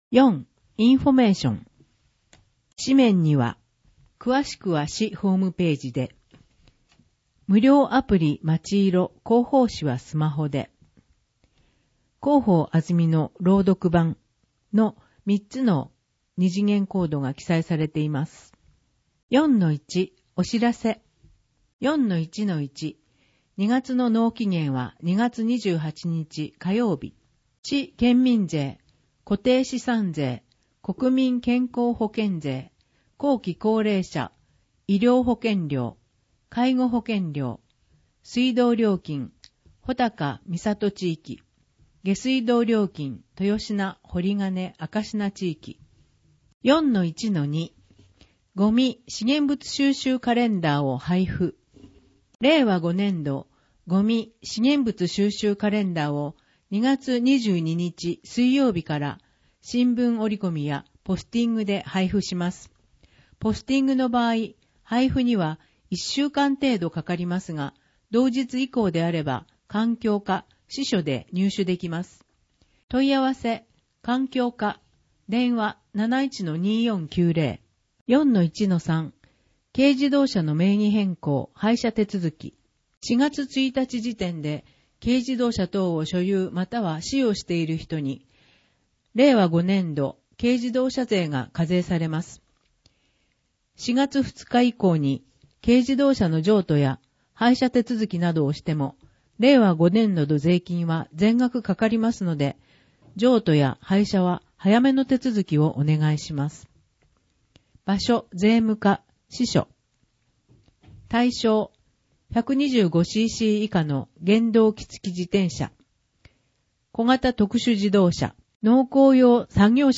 広報あづみの朗読版353号（令和5年2月22日発行号) - 安曇野市公式ホームページ
「広報あづみの」を音声でご利用いただけます。この録音図書は、安曇野市中央図書館が制作しています。